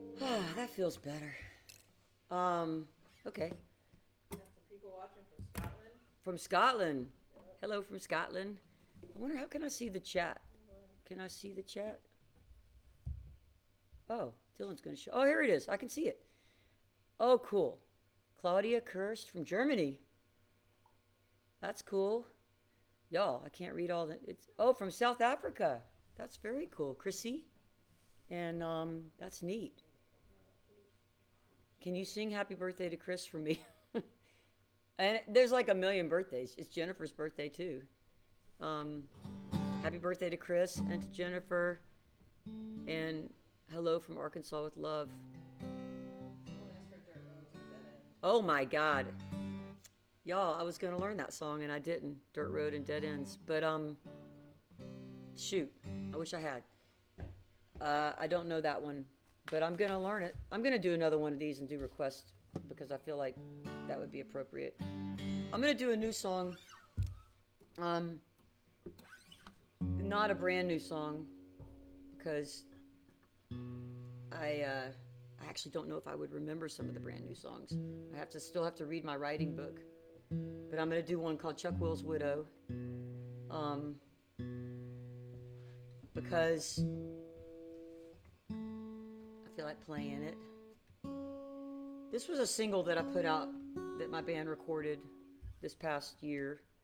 (captured from the facebook live stream)
03. talking with the crowd (1:36)